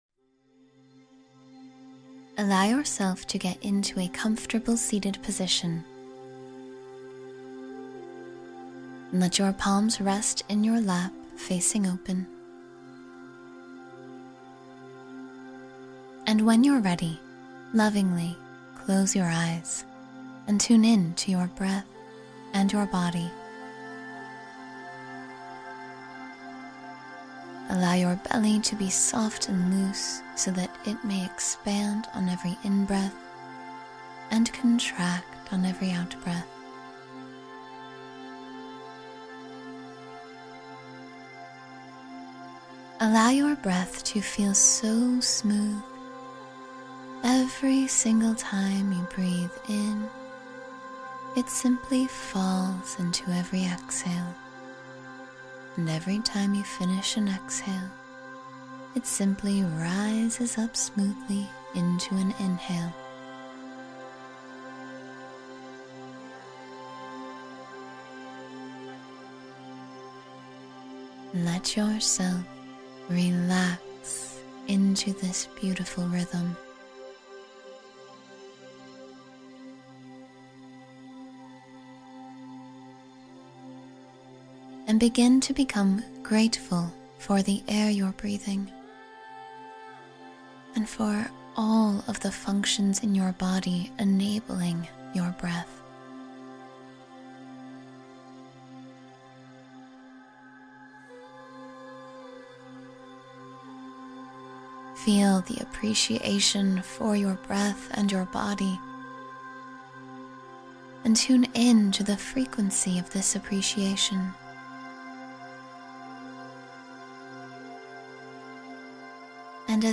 Relax Mind and Body in 10 Minutes — Guided Meditation for Stress Relief